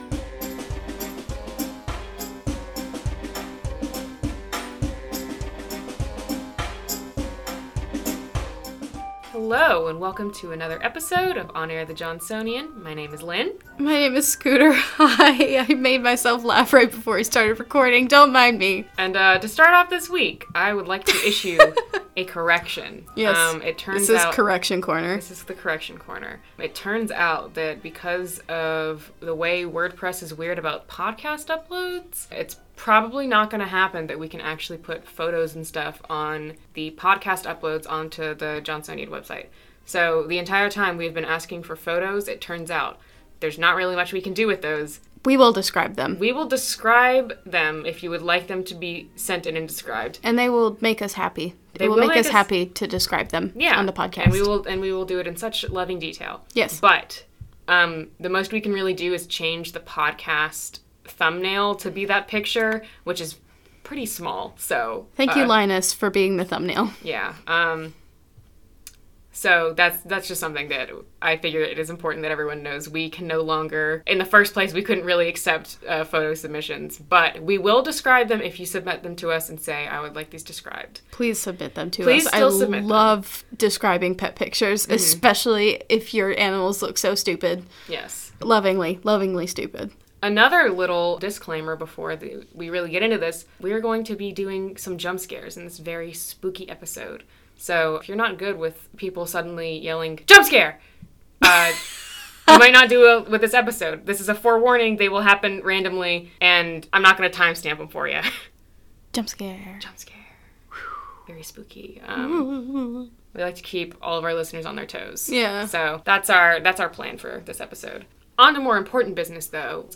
This week, your hosts rank Halloween creatures and supernatural entities based on how well they’d fare on Winthrop’s campus.